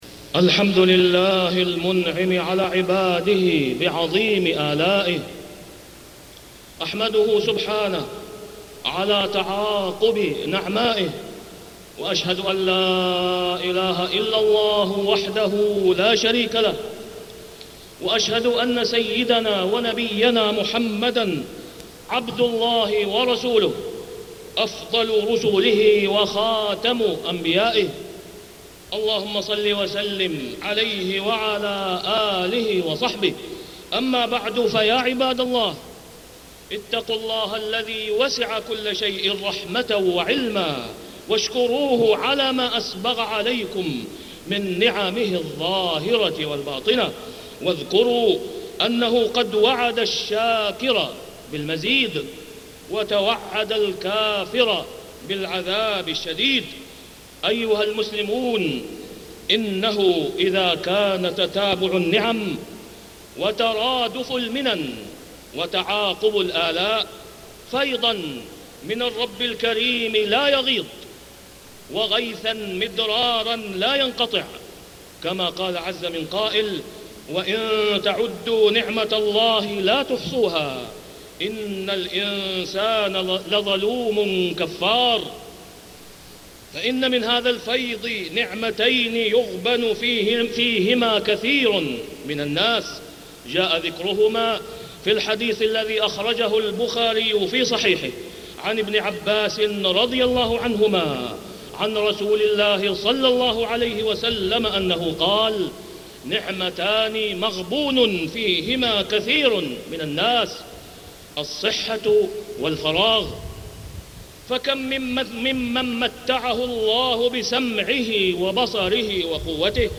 تاريخ النشر ٣ جمادى الأولى ١٤٢٦ هـ المكان: المسجد الحرام الشيخ: فضيلة الشيخ د. أسامة بن عبدالله خياط فضيلة الشيخ د. أسامة بن عبدالله خياط نعمتا الصحة والفراغ